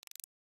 Expand.wav